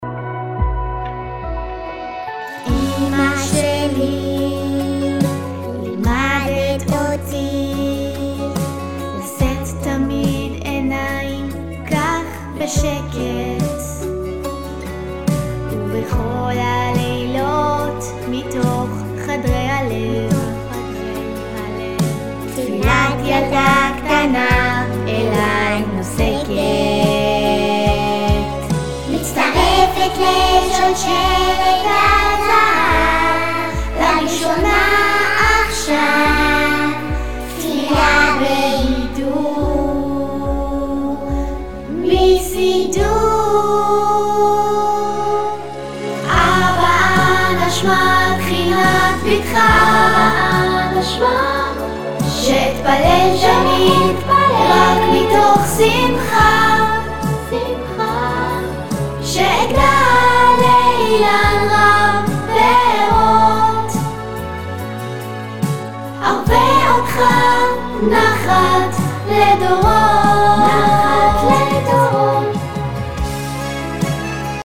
חתוך-מסיבת-סידור-לפני-מאסטרינג.mp3